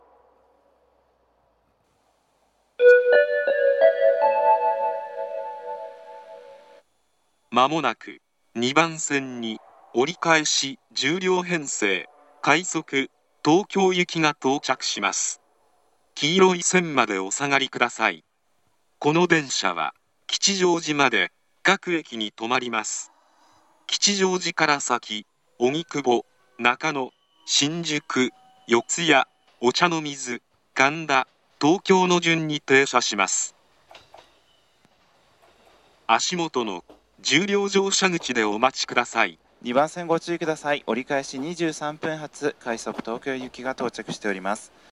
青梅市内の駅です。
駅員による放送が比較的被り易いです。
接近放送
長めに鳴り易くなります。